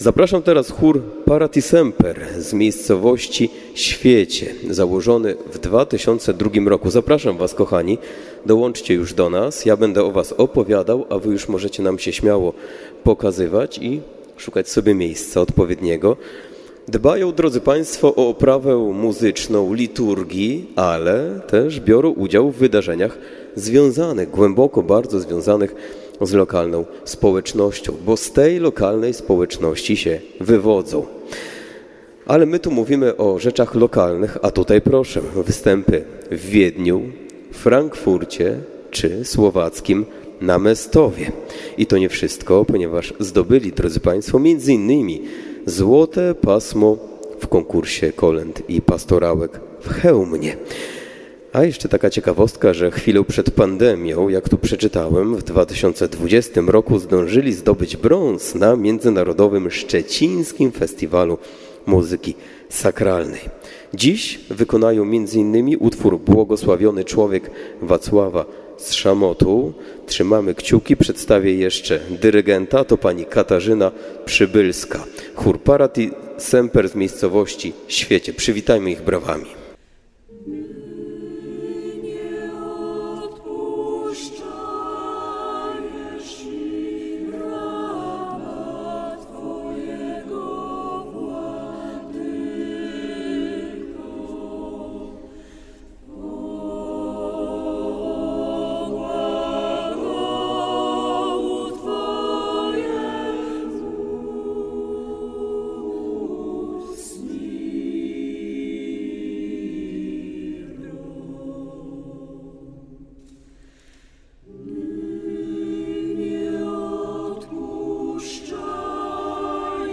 Chór „Parati Semper”, Świecie